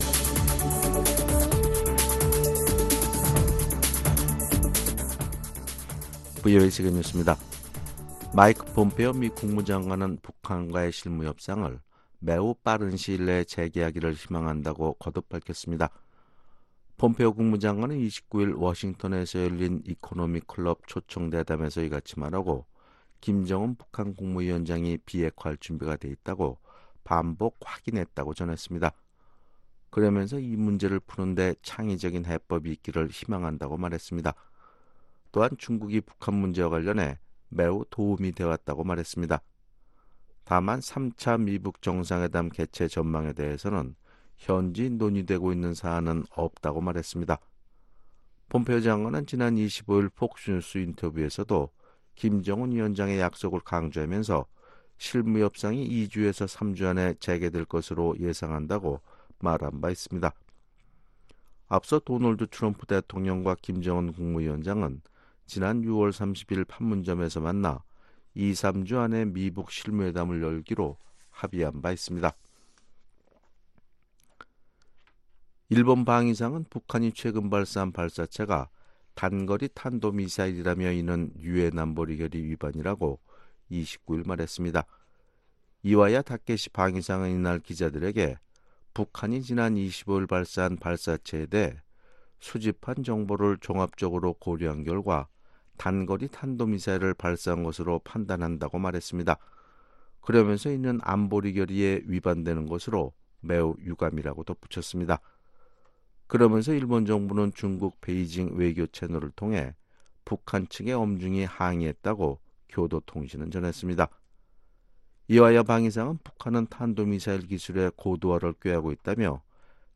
VOA 한국어 아침 뉴스 프로그램 '워싱턴 뉴스 광장' 2019년 7월 30일 방송입니다. 한국의 전문가들은 북한이 연일 대남 비난공세를 이어가는 것은 미국과의 물밑 접촉에서 자신들의 요구가 받아들여지지 않는데 대한 불만을 표출한 것으로 풀이했습니다. 도널드 트럼프 대통령이 올해 7월 27일을 한국 전쟁 참전용사 정전기념일로 선포했습니다.